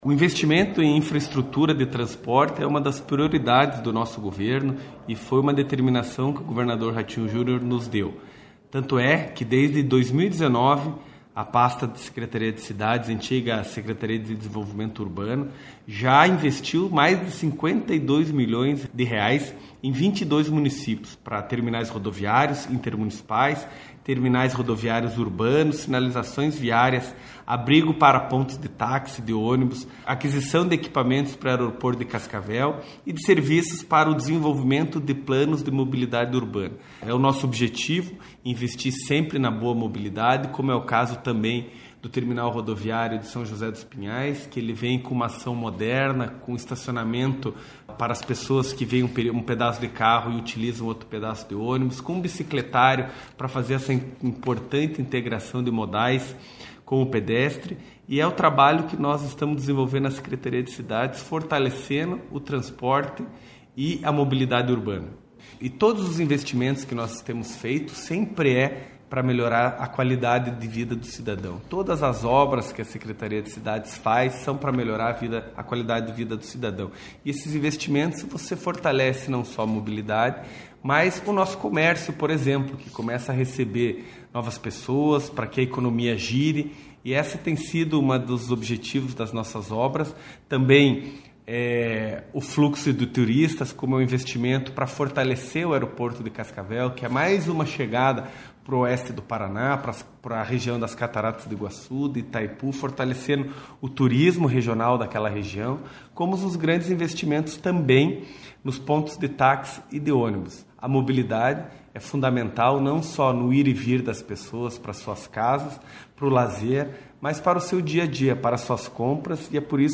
Sonora do secretário estadual das Cidades, Eduardo Pimentel, sobre os investimentos de R$ 53 milhões em transporte feitos pela pasta desde 2019